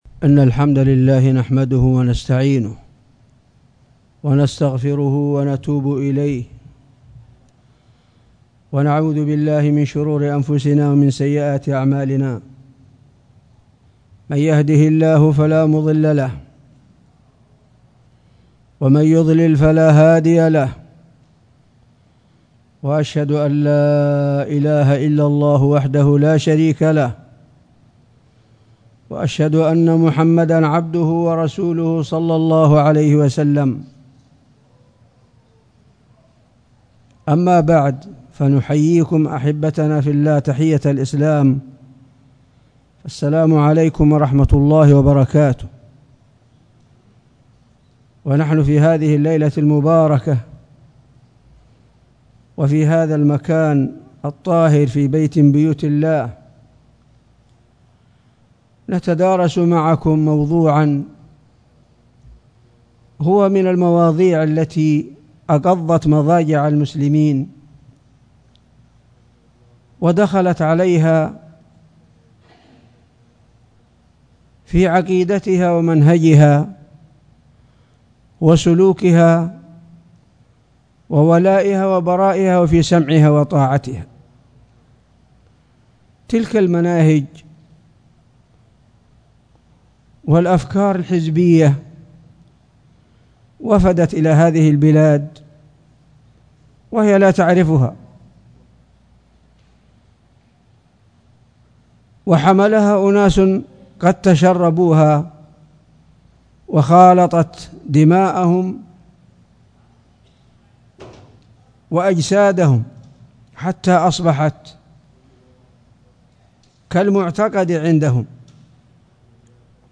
محاضرة
جامع قرية الحجفار بصامطة